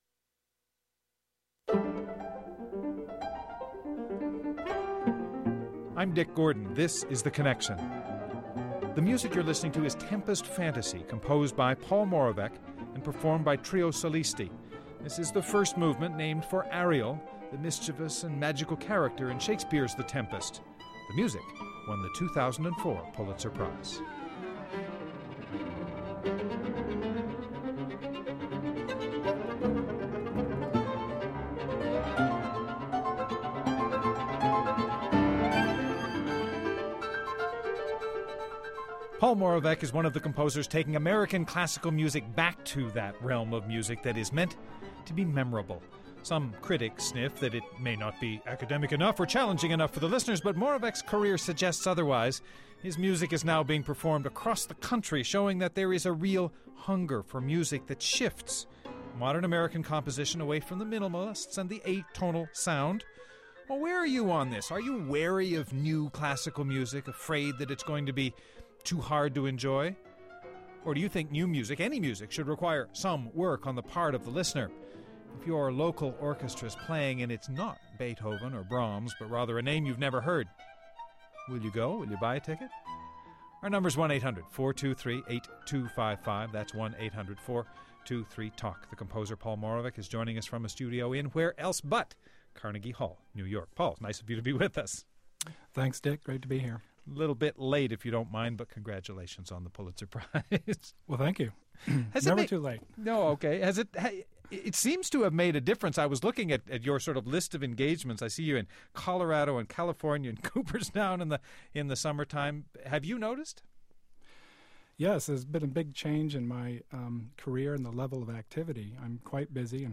Guests: Paul Moravec, winner of the 2004 Pulitzer Prize for Music for his contemporary classical piece, “Tempest Fantasy”;Terry Teachout, music critic for Commentary, a monthly opinion magazine.